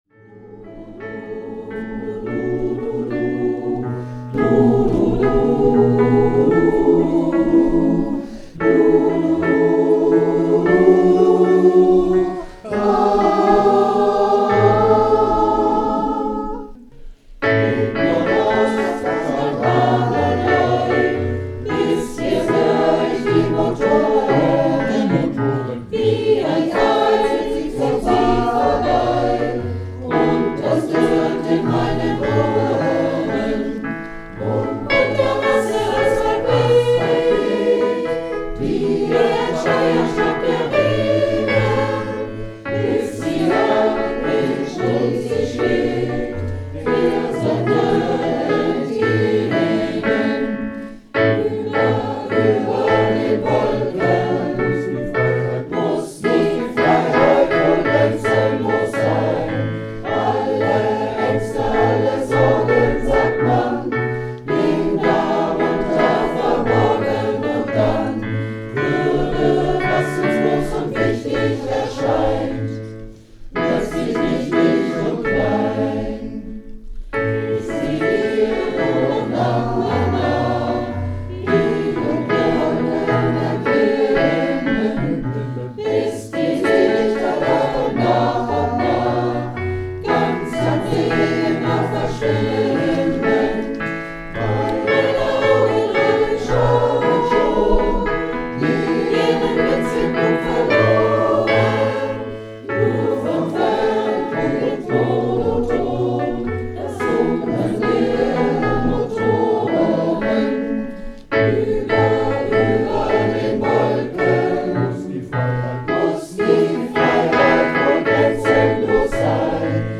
Reinhard Mey im Chor
Hörbeispiele vom ersten und zweiten Wochenende: